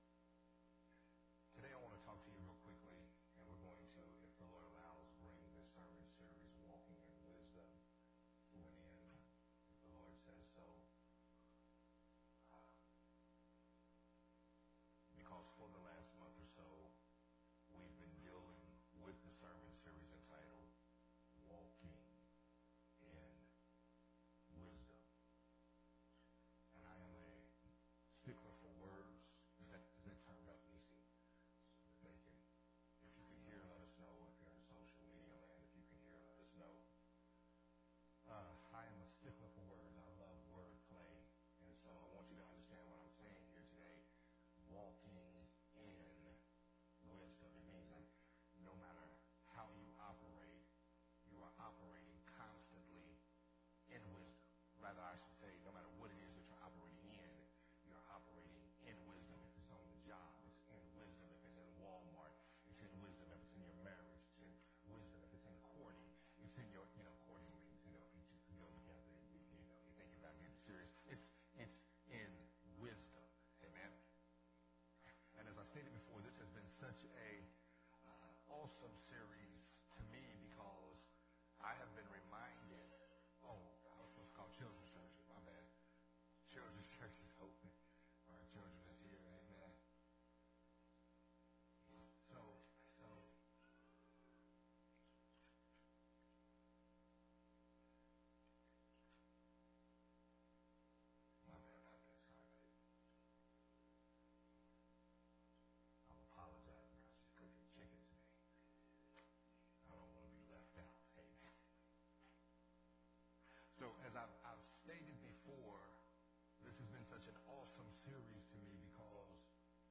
sermon series
recorded at Unity Worship Center on February 27, 2022.